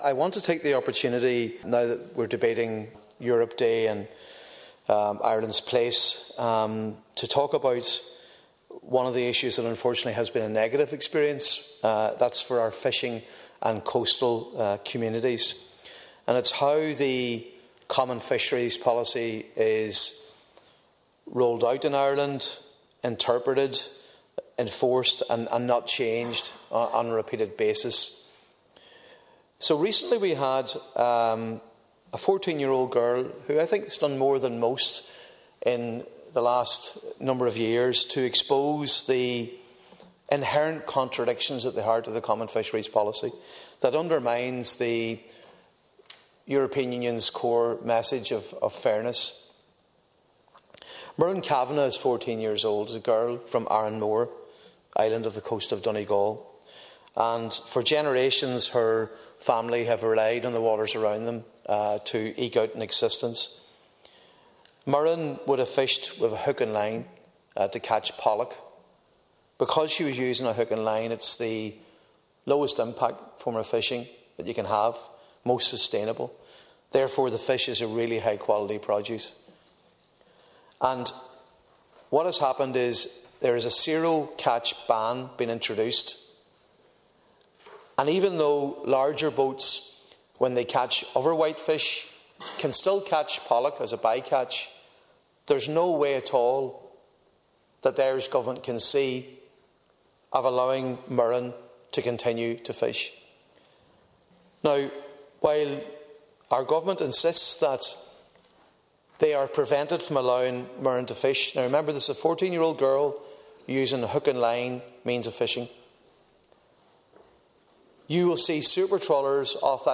Deputy MacLochlainn’s speech in full –